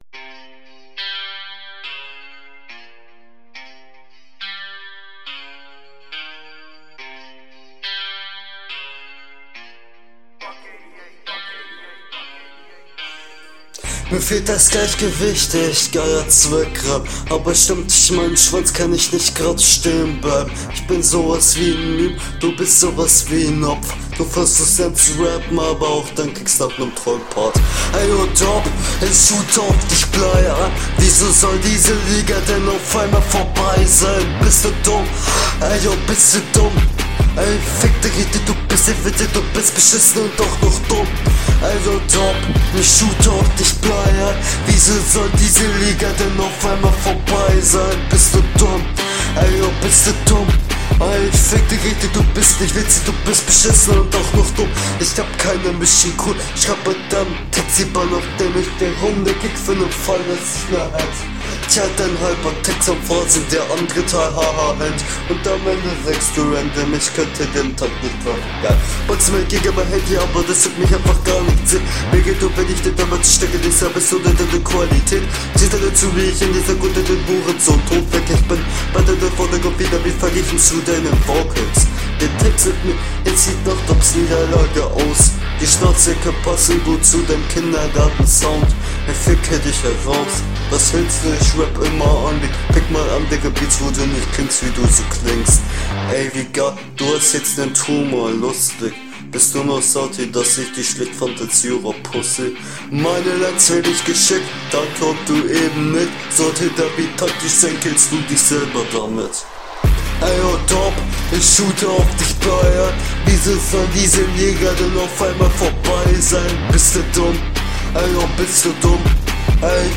Flow: ich verstehe manchmal was und manchmal garnichts gerappt ist das besser als ich erwartet …
Flow: Flow ist echt gut für ihm und er hat nur ein paar takt fehler, …
Flow: Langweilig triffst aber den Takt meistens Text: Naja muss ich nicht viel zu sagen …